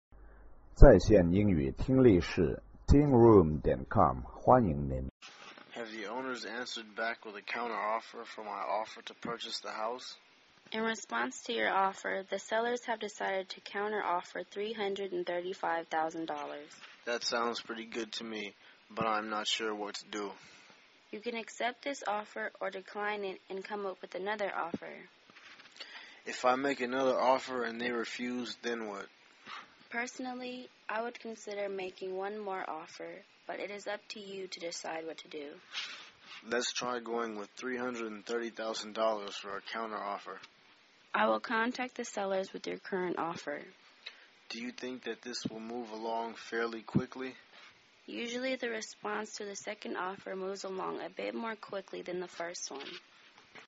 英语情景对话-Discussing Counter-Offer(3) 听力文件下载—在线英语听力室